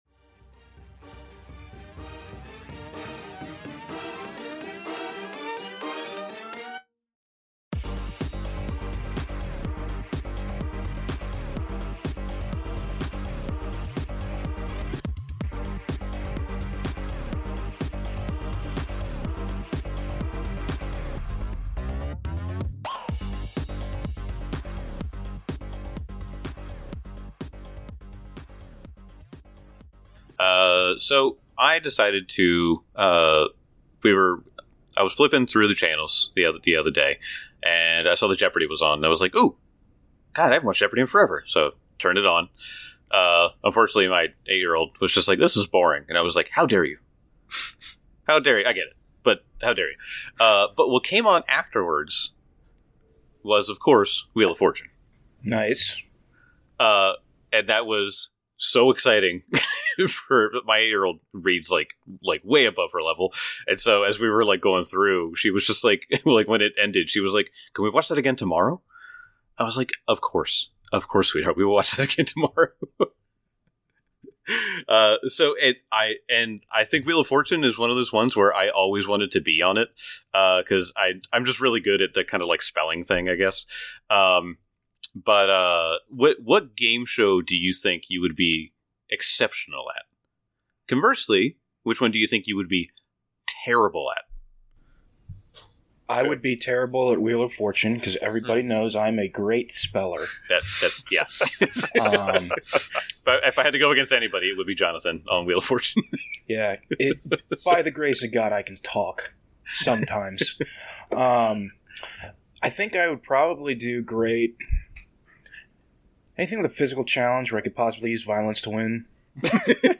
Actual play podcast of the Pathfinder 2e, Age of Ashes adventure path produced by Paizo. Five nerds who love beer, whiskey, and playing make-believe.